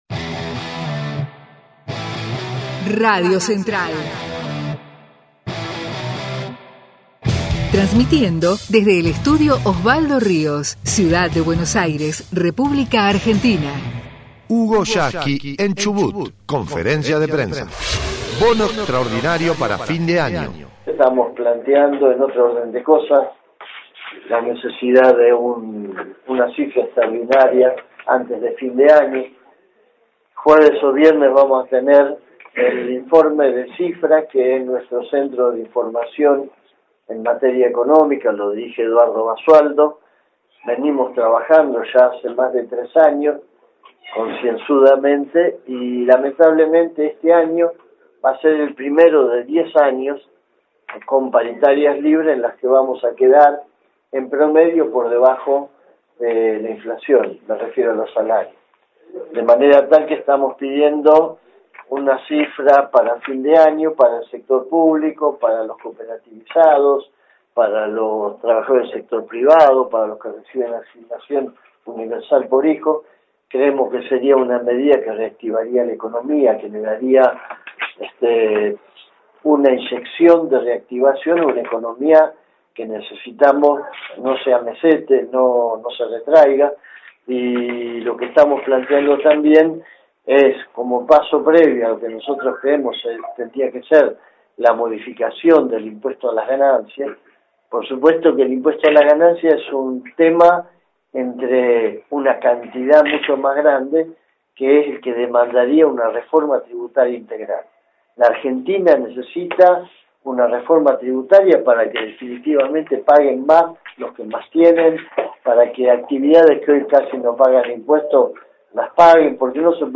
HUGO YASKY en CHUBUT - conferencia de prensa - 2ª parte: BONO EXTRAORDINARIO
El secretario General de la Central de Trabajadores de la Argentina (CTA) en la provincia patagónica.